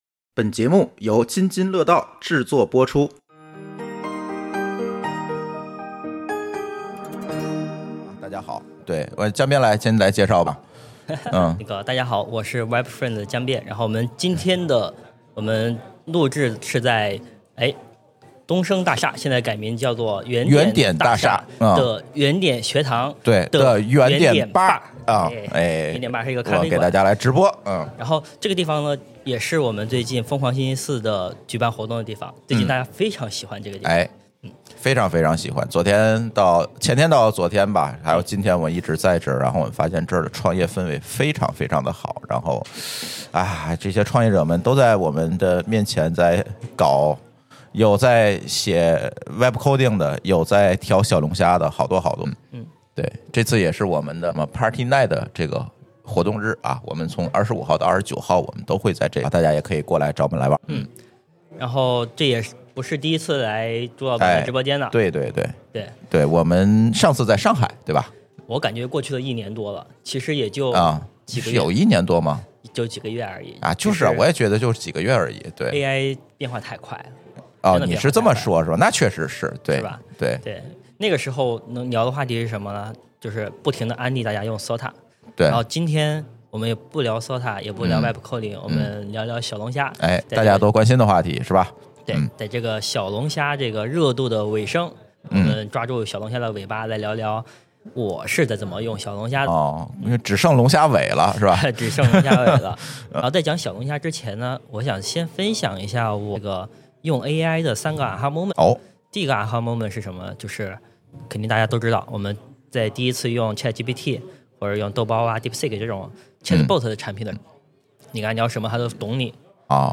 本期播客录制于北京原点大厦（原名东升大厦）的原点Bar咖啡馆，这是一个创业氛围浓厚的地方，写代码的敲击声与咖啡的香气交织在一起。